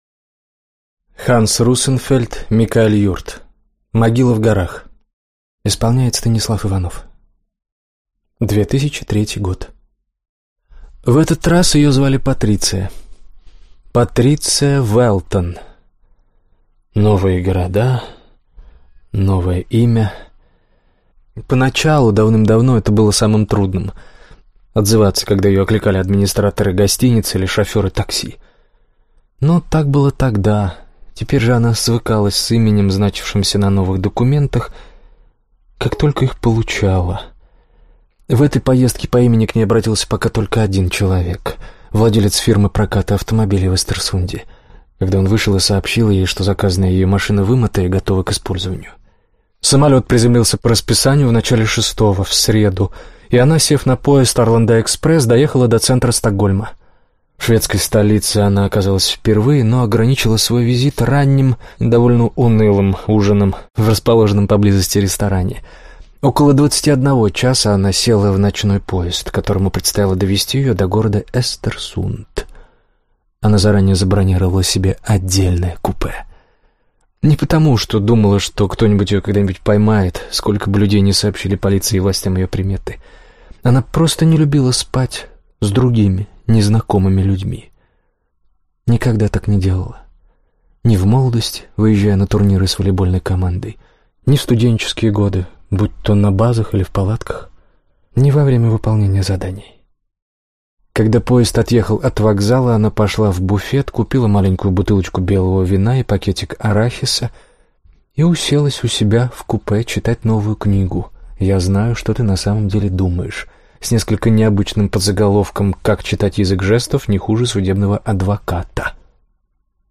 Аудиокнига Могила в горах | Библиотека аудиокниг